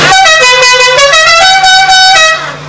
dixie horn